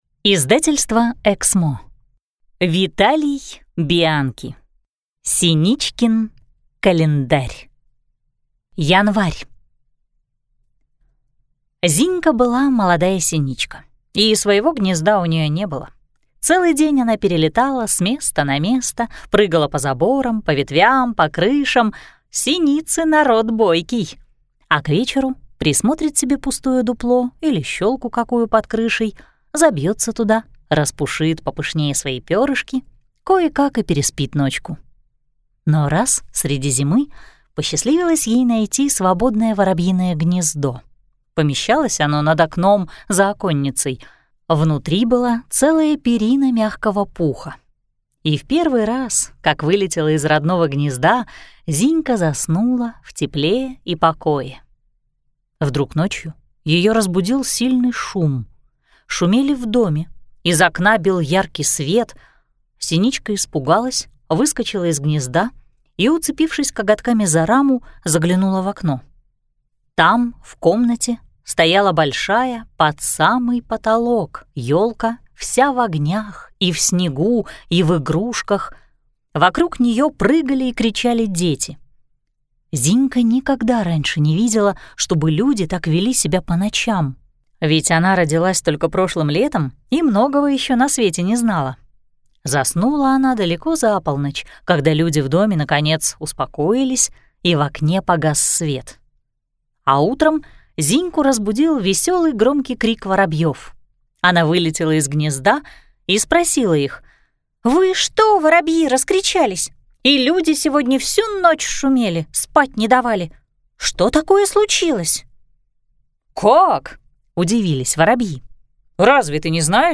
Аудиокнига Синичкин календарь | Библиотека аудиокниг